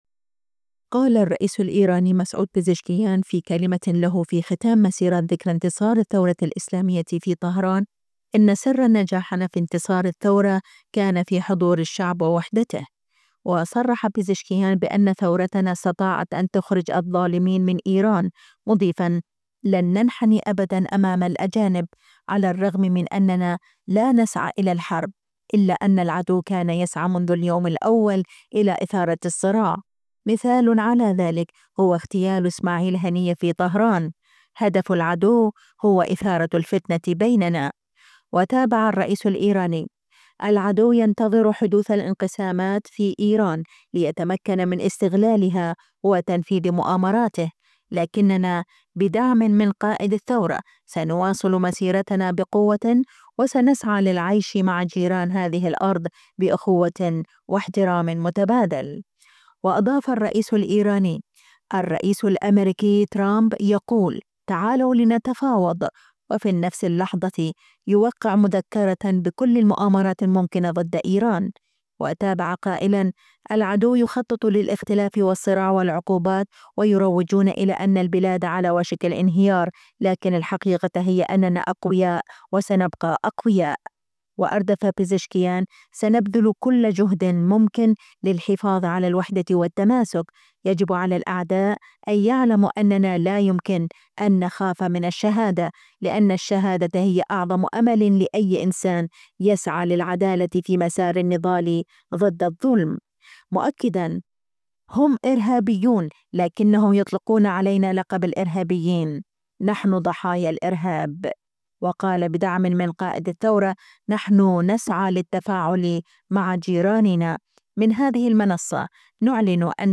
قال الرئيس الإيراني مسعود بزشكيان في كلمة له في ختام مسيرات ذكرى انتصار الثورة الإسلامية في طهران إن "سر نجاحنا في انتصار الثورة كان يكمن في حضور الشعب ووحدته."